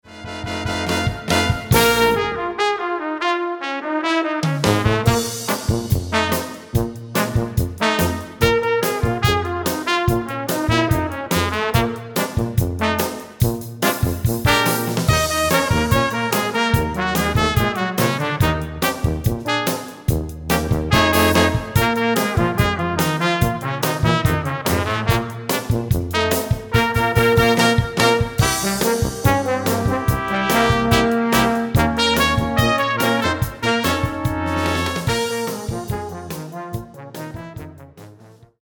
Voicing: 5 Brass w/Rhy